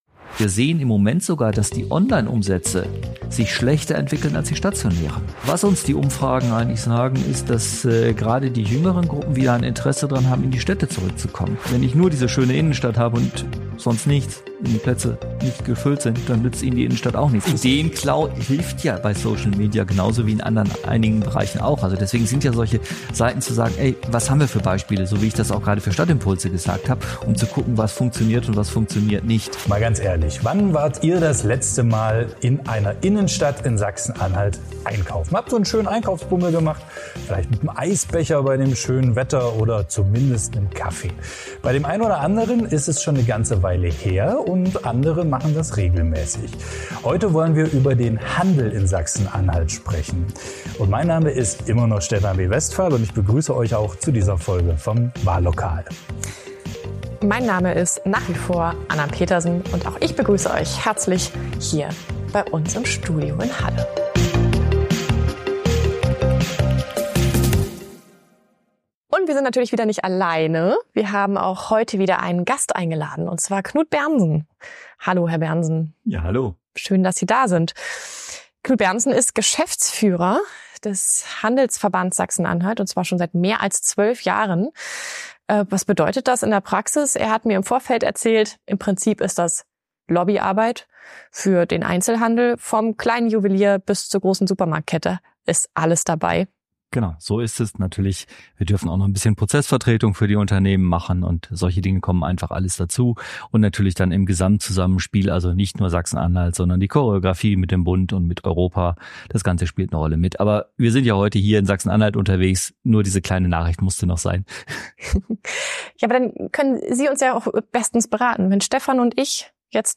diskutiert mit den Moderatoren